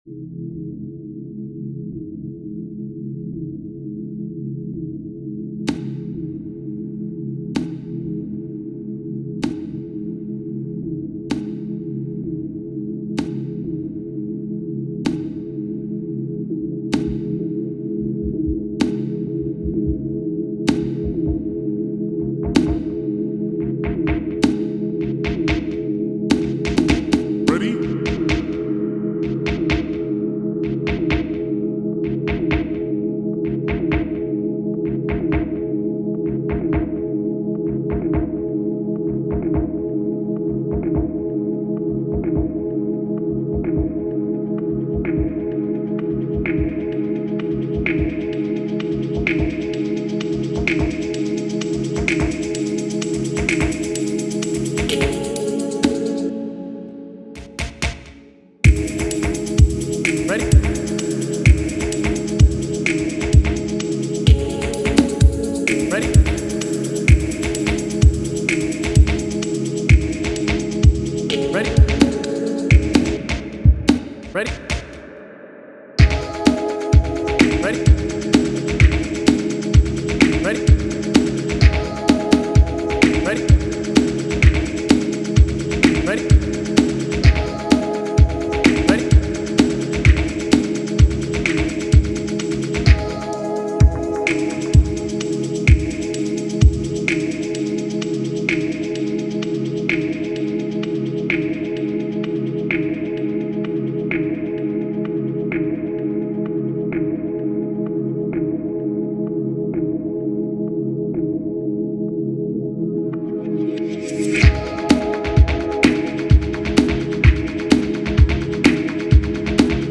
It’s all the sample track apart from a vocal one shot I used - really enjoyed working with this track actually - I squeezed a little staccato guitar thing in the last movement out of the bassline… somehow - ended up being one of my favourite elements even though it’s quite subtle in the mix.